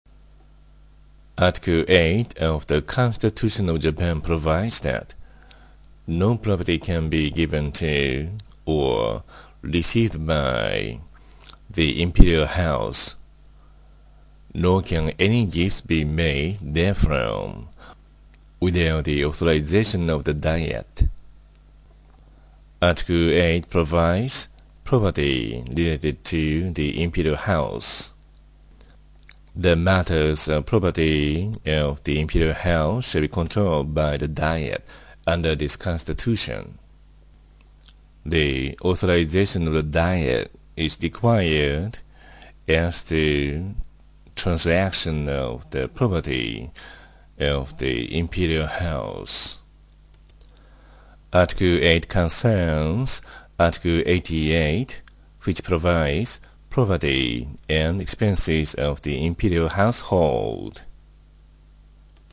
英語音声講義